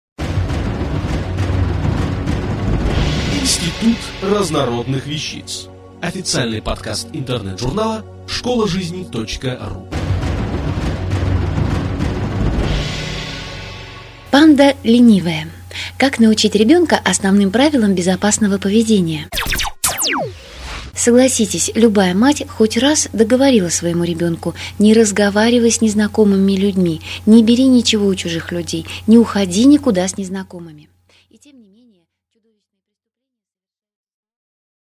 Аудиокнига Как научить ребёнка основным правилам безопасного поведения?